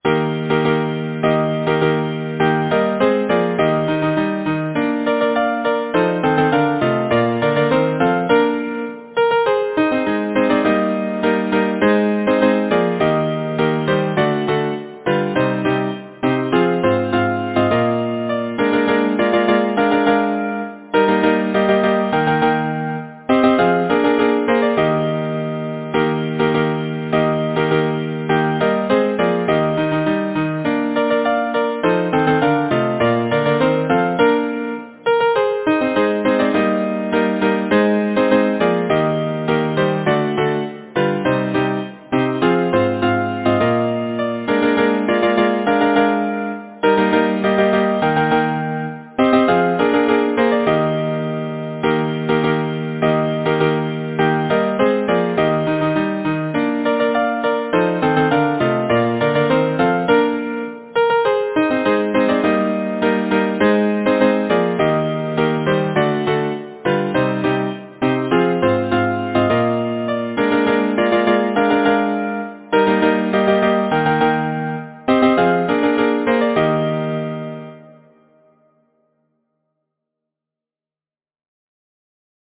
Title: Spring Time Composer: Ann Mounsey Lyricist: William Bartholomew Number of voices: 4vv Voicing: SATB Genre: Secular, Partsong
Language: English Instruments: A cappella